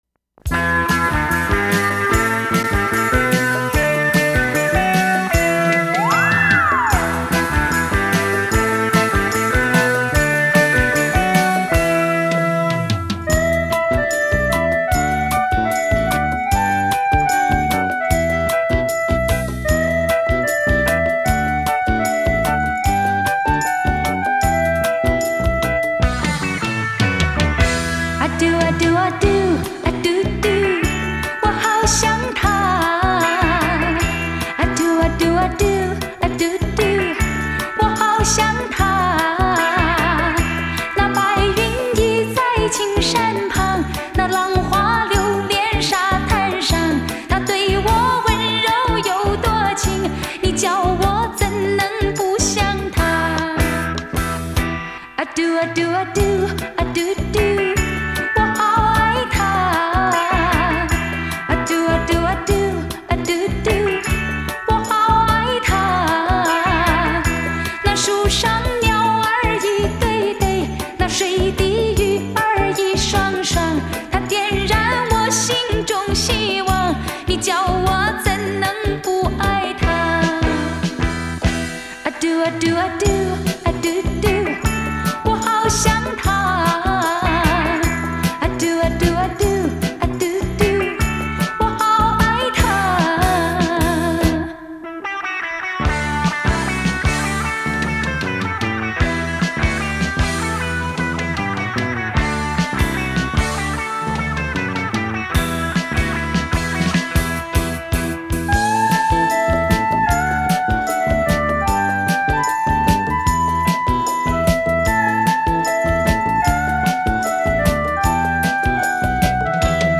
专辑风格：国语、流行歌曲